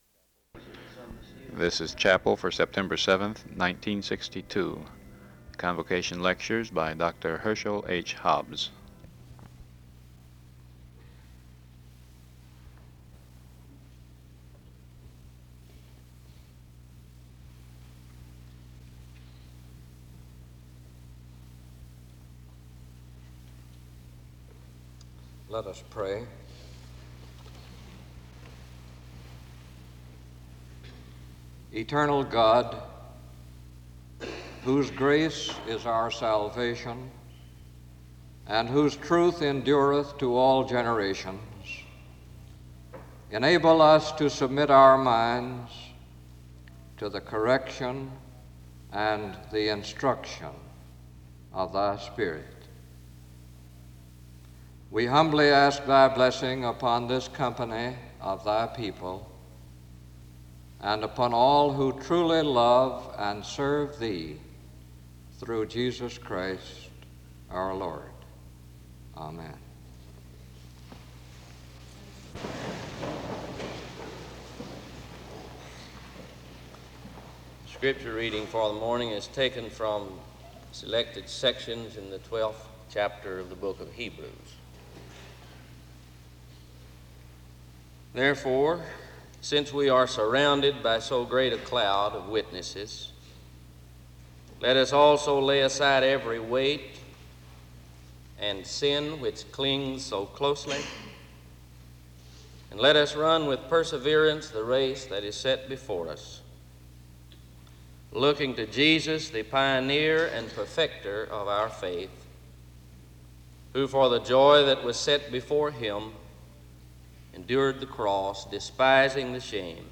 There is a closing prayer from 1:04:31-1:05:00.